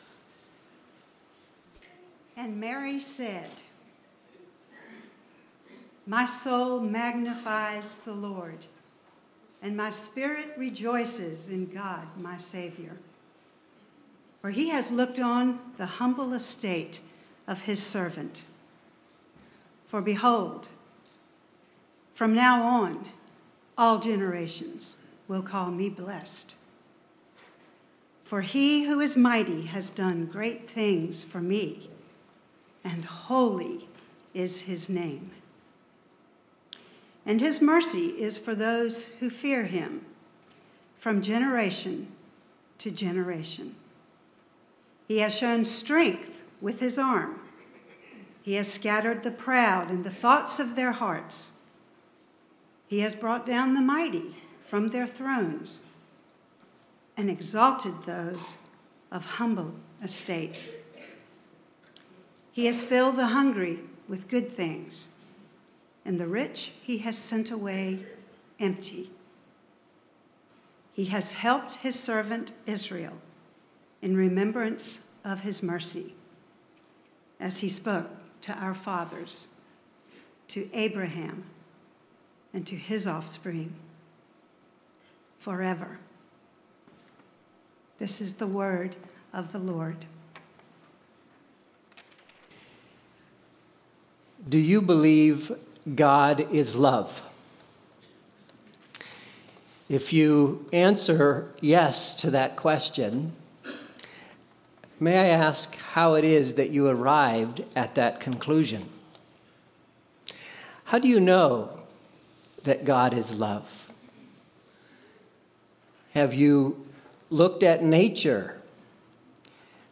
The Gift of Love- Our Christmas Cantata